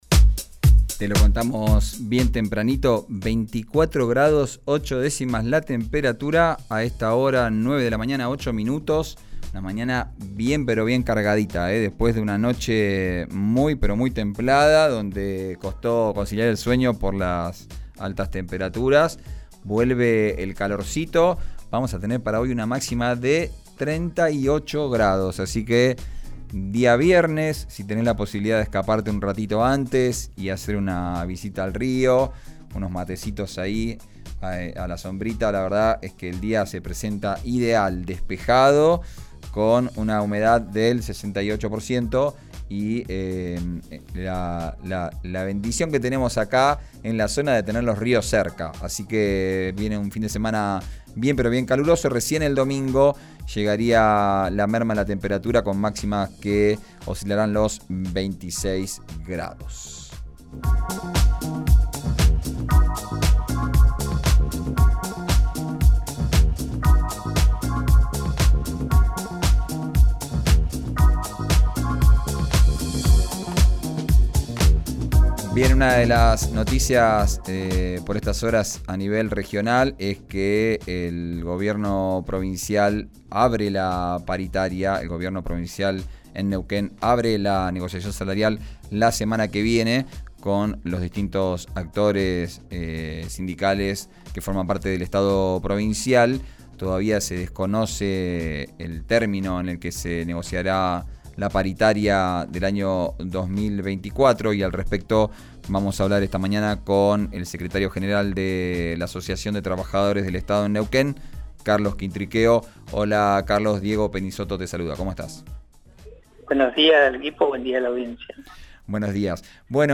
En diálogo con RIO NEGRO RADIO cuestionó una posible propuesta que incluya una «cláusula gatillo».